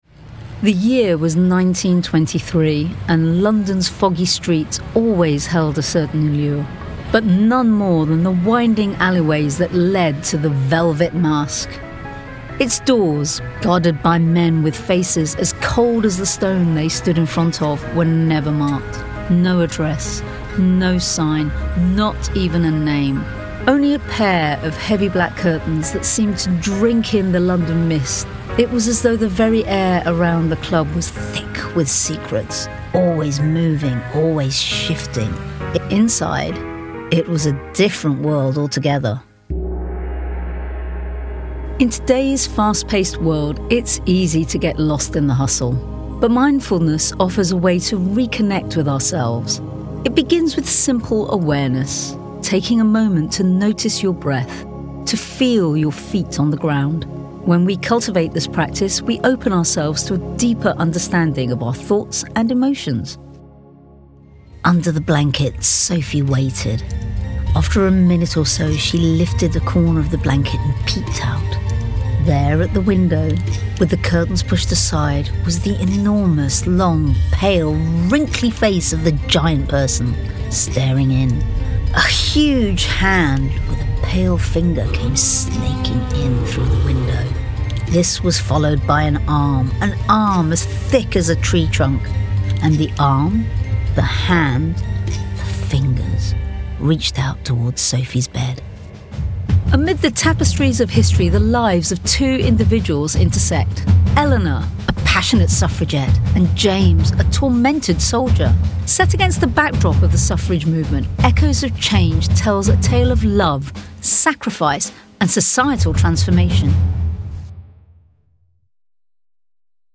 Radio & Voiceovers
I can provide voiceovers with a warm, clear and engaging voice which exudes confidence and professionalism. I have a slight maturity in my voice, as well as a youthful quality.
Audiobook